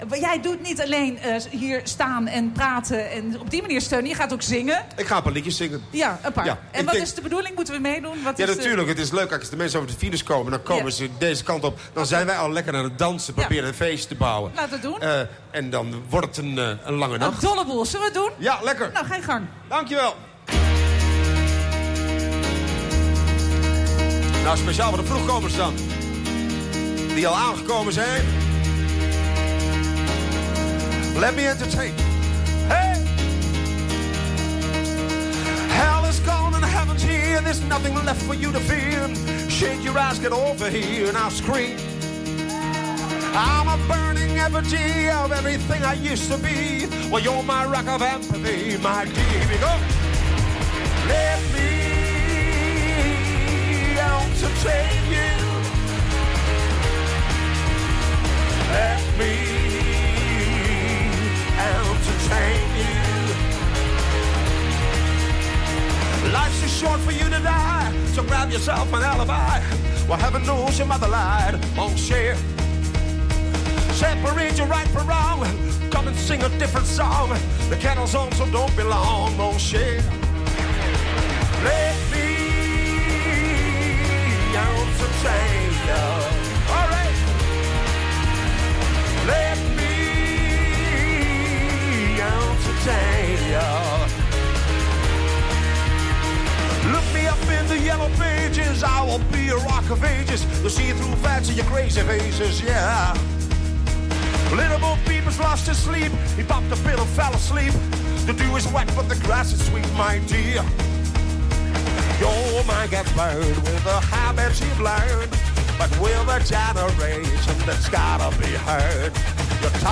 Met zijn toestemming hierbij een deel van het optreden.